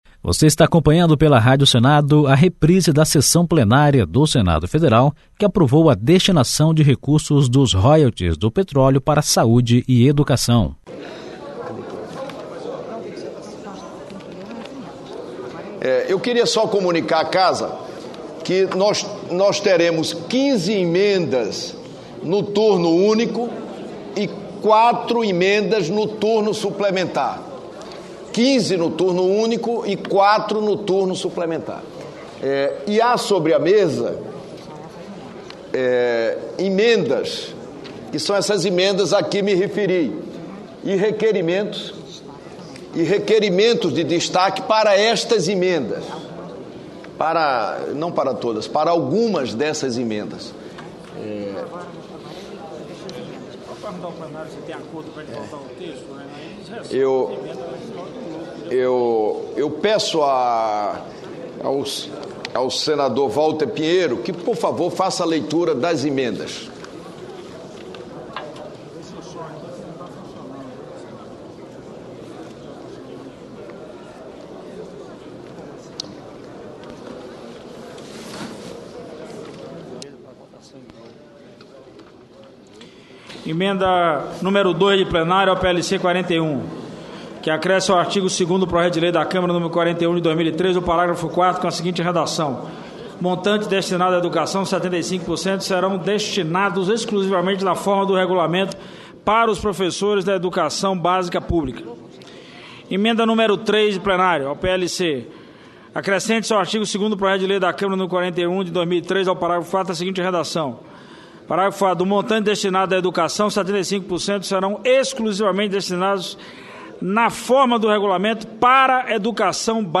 Sessão que aprovou destinação de royalties do petróleo para educação (4)